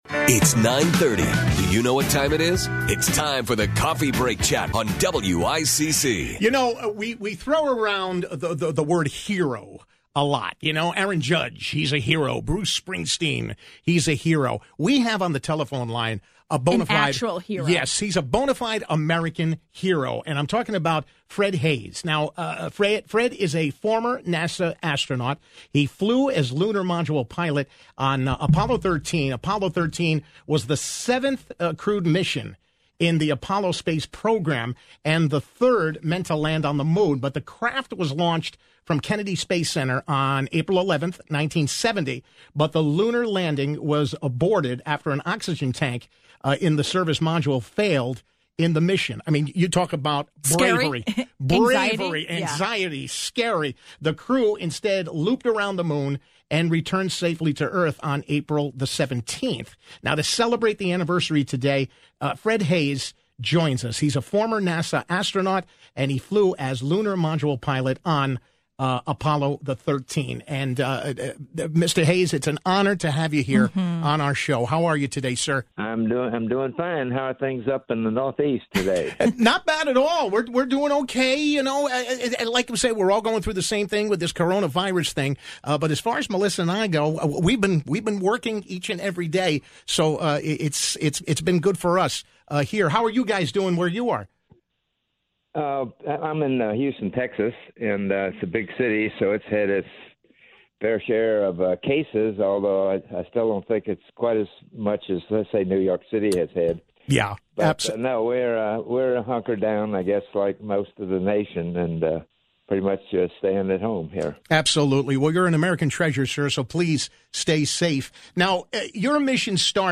The former NASA astronaut joined us live to take us back to the mission and talk about the movie that portrayed what happened years later.